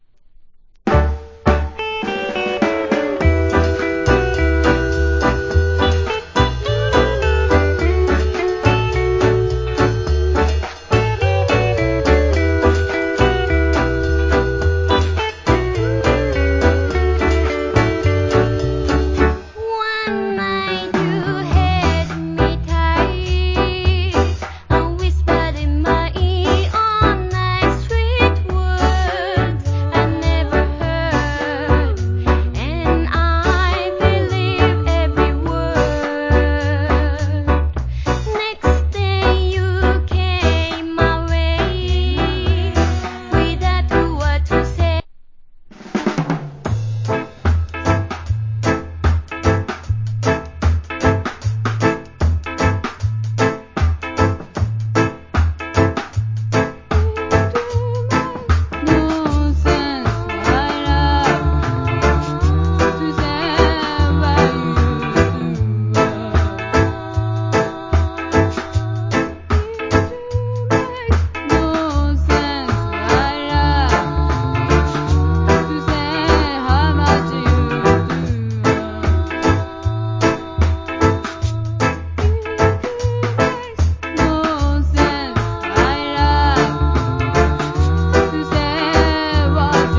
Nice Rock Steady. 1996.